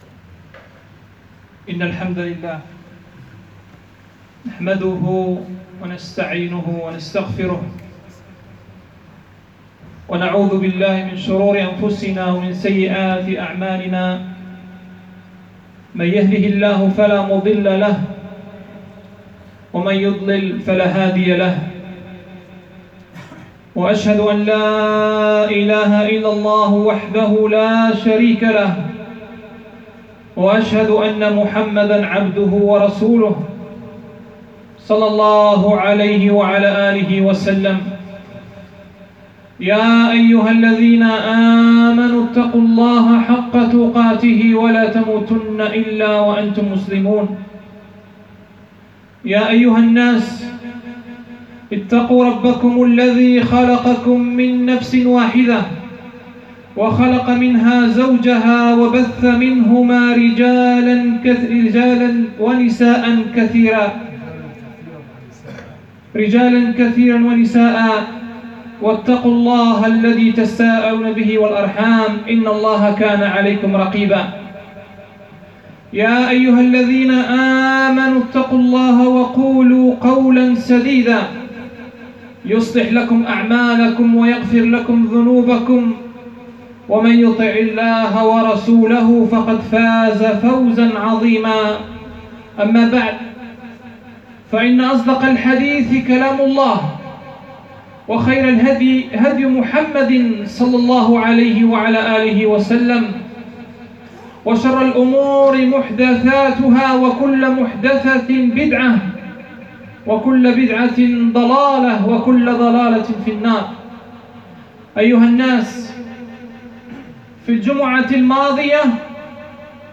فضل يوم عرفة وبعض أحكام العيد الخطب المنبرية بدار القران